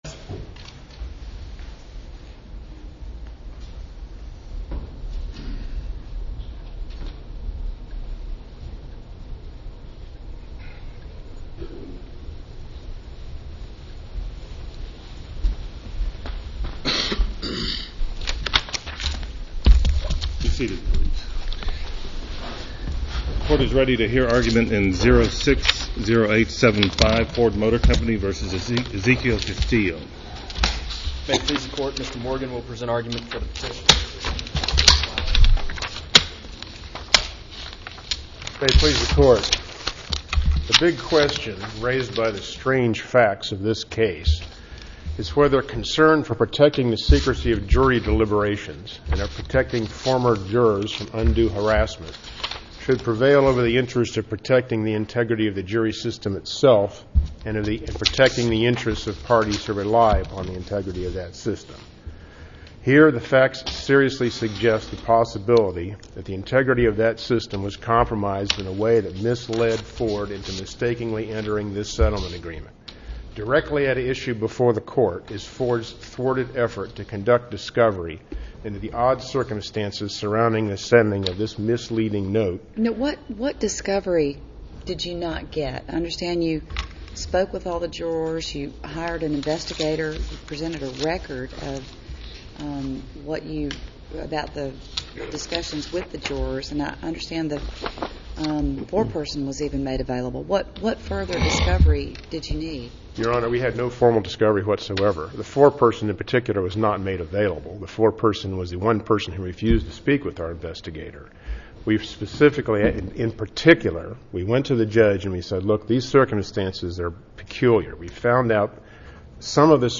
Case No. 06-0005 Oral Arguments Audio (MP3)